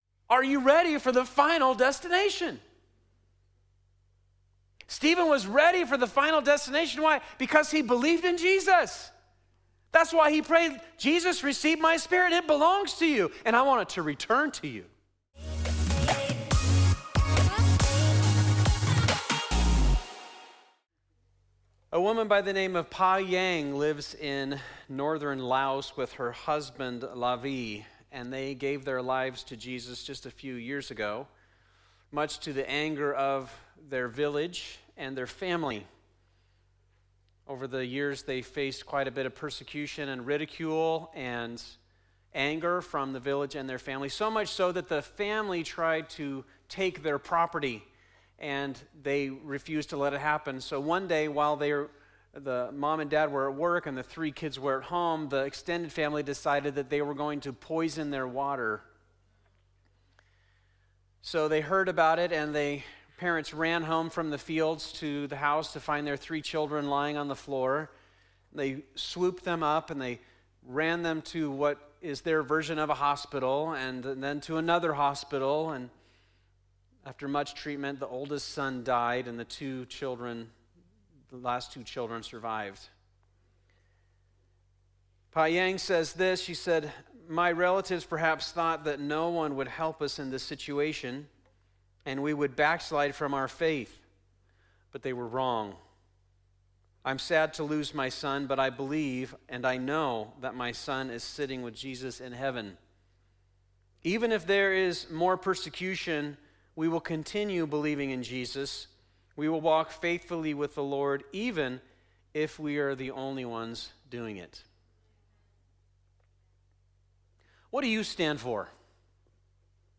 2025 The After Party Faith Grudges Martyrs Wisdom Sunday Morning "The After Party" is our series at Fusion Christian Church on the book of Acts.